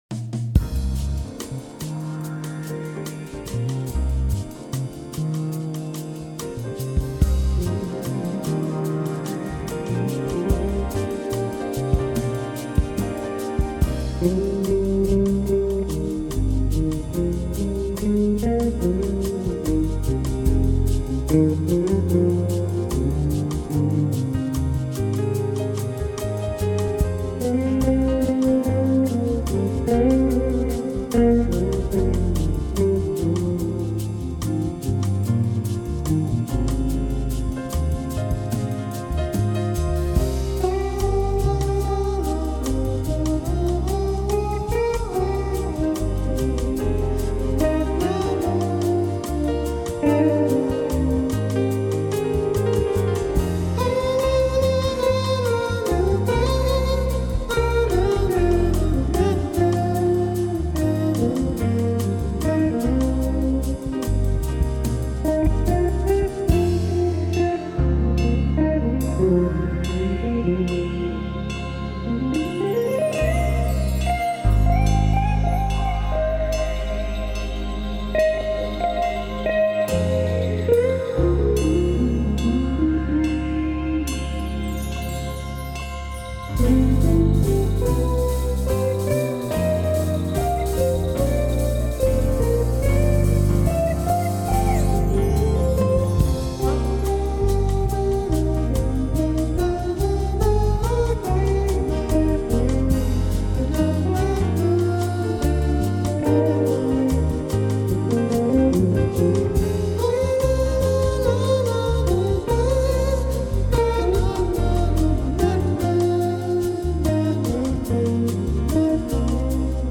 LATIN JAZZ
bass
guitar
drums
keyboards
vocals
percussion